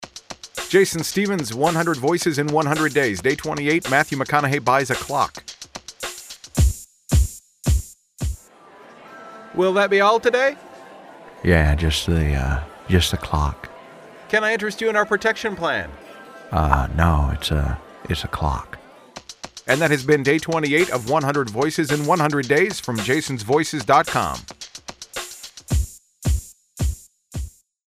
Seriously, I hope enjoy my Matthew McConaughey impression – and then go buy a clock pretending you’re him.
Tags: celebrity impersonations, Matthew McConaughey Impression